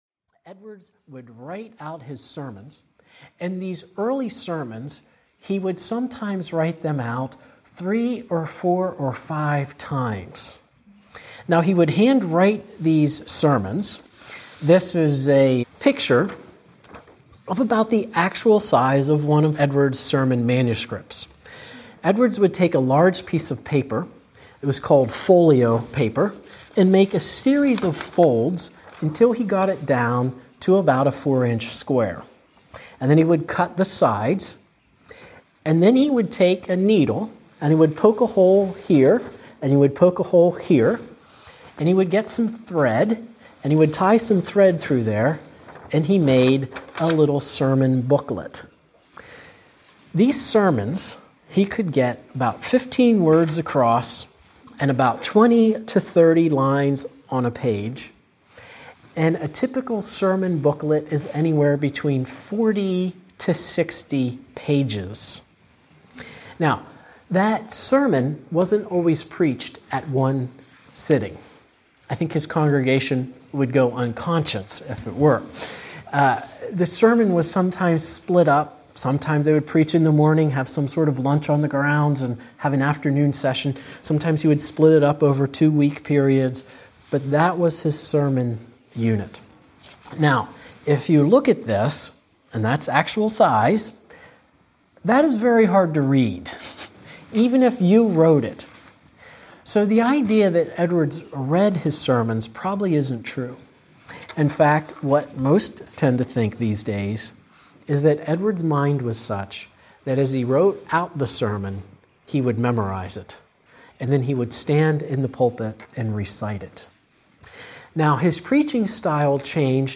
Jonathan Edwards Audiobook